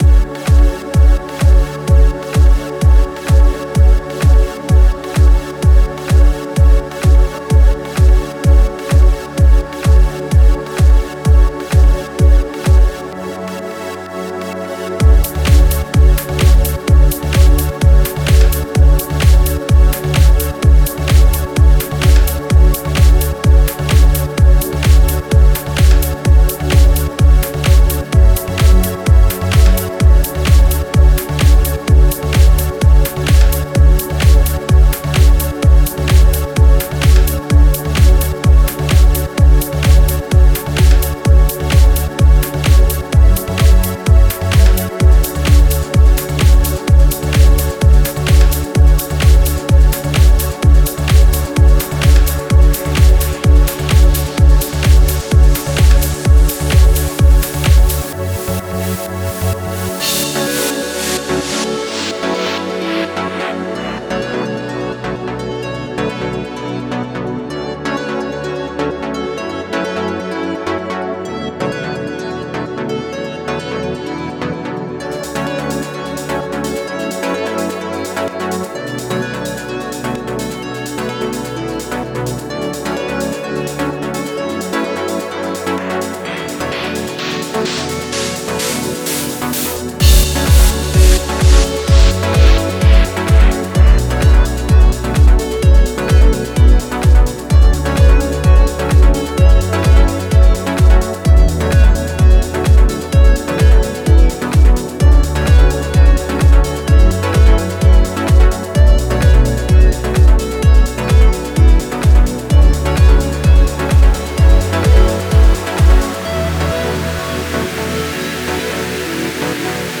Стиль: Progressive House